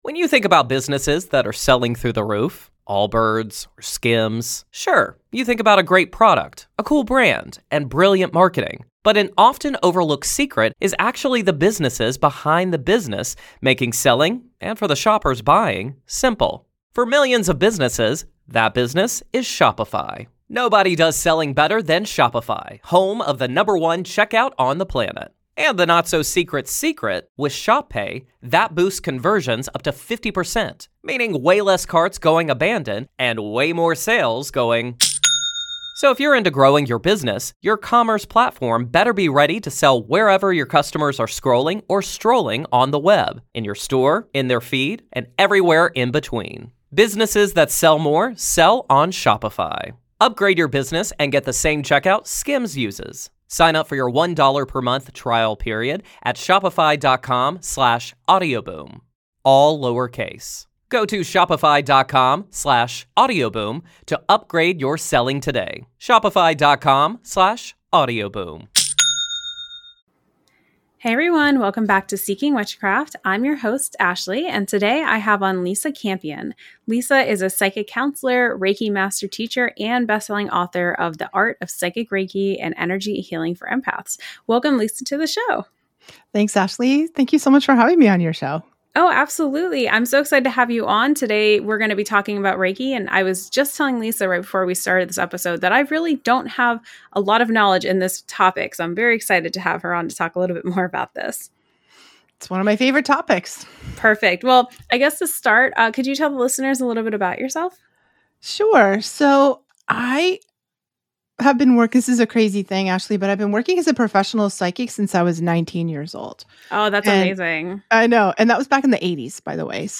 Whether you're completely new to Reiki or just curious about energy healing, this conversation is packed with foundational insights.